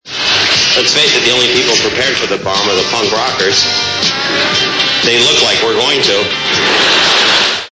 from a promo for Louie Anderson on the Showtime comedy spotlight, circa 1985.